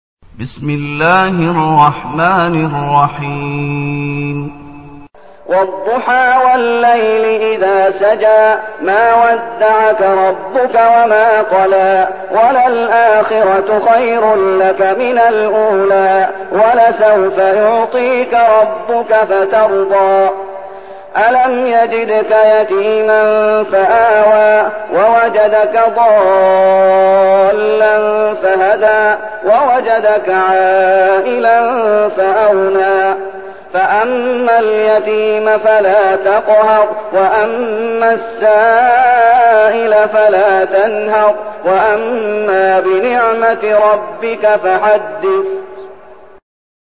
المكان: المسجد النبوي الشيخ: فضيلة الشيخ محمد أيوب فضيلة الشيخ محمد أيوب الضحى